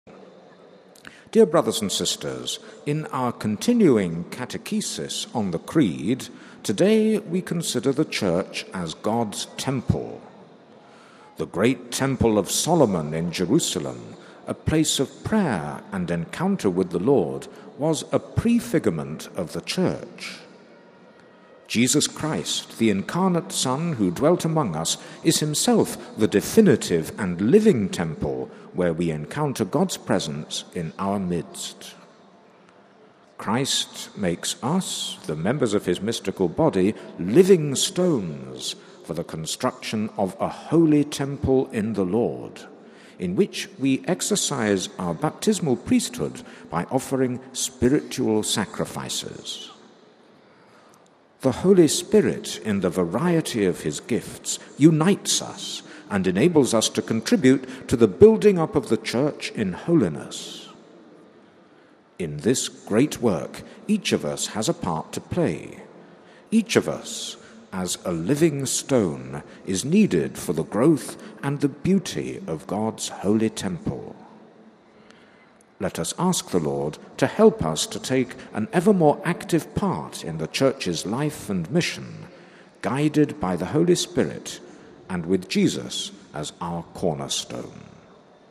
But before that, the aides greeted the Pope on behalf of the various language groups.